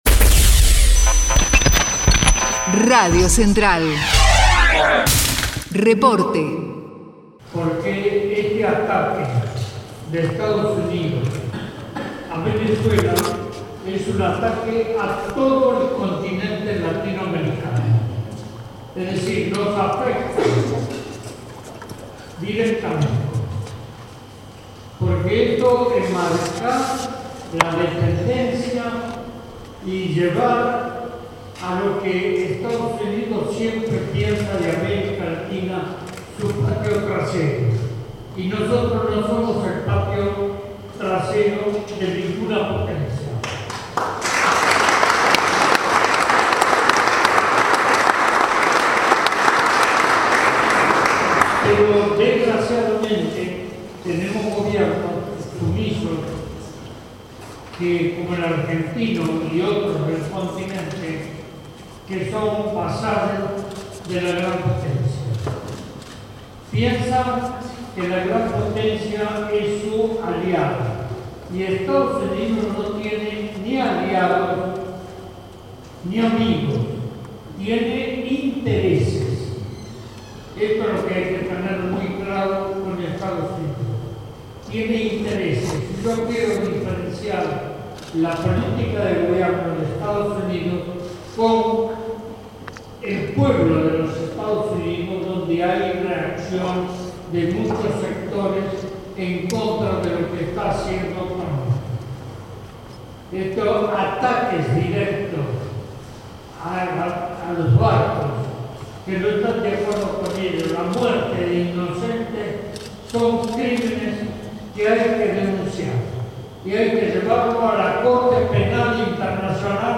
ADOLFO PÉREZ ESQUIVEL: CONTRA LA INTERVENCIÓN MILITAR DE EE.UU. EN VENEZUELA - ACTO EN LA CTA